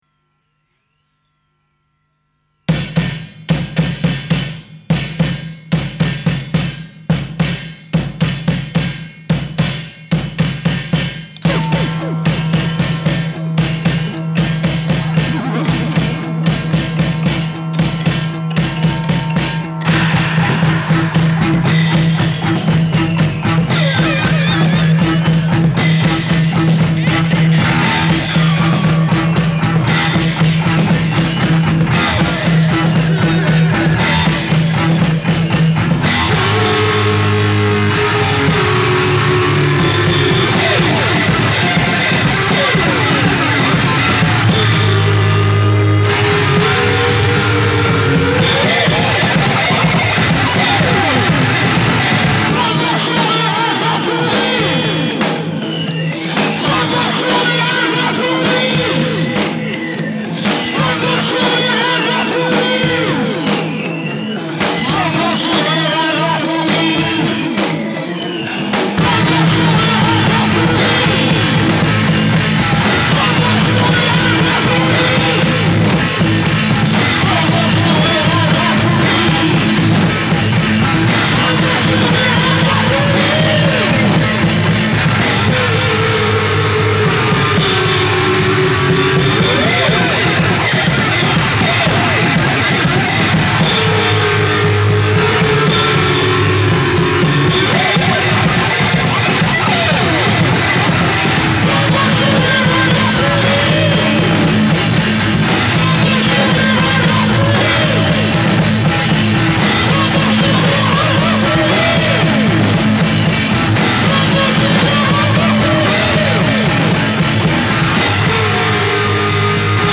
de la pop enregistré à la maison
guitares déglingo